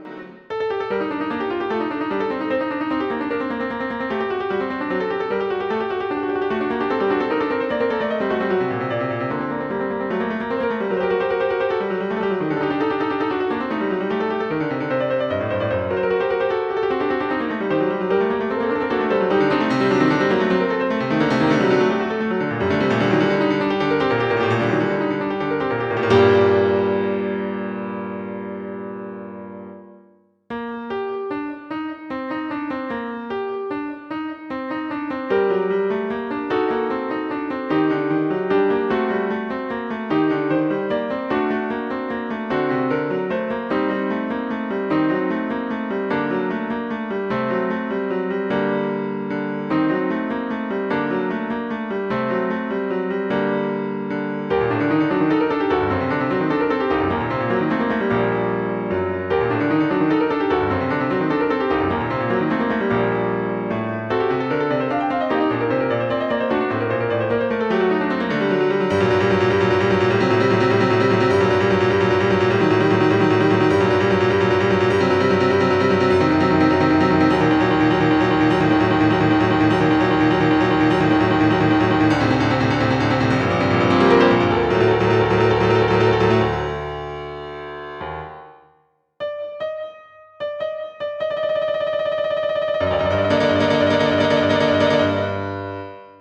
Op.28 - Piano Music, Solo Keyboard - Young Composers Music Forum